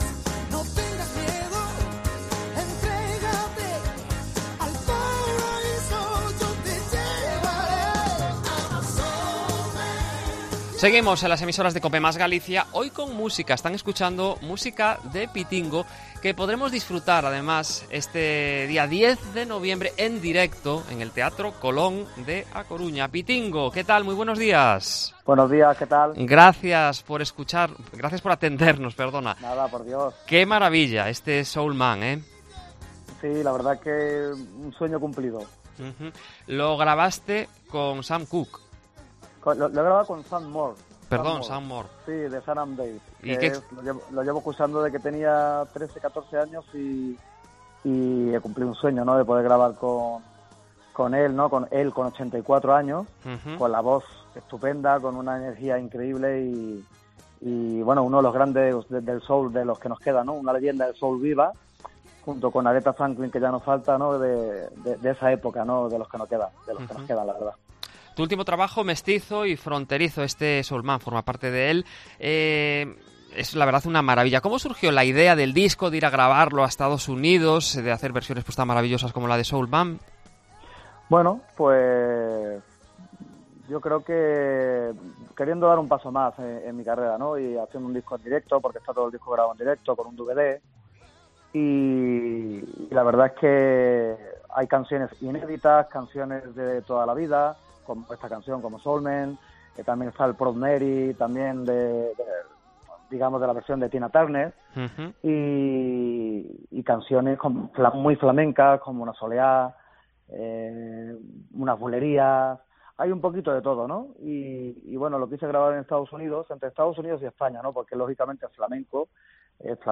Entrevista a Pitingo, en Cope Galicia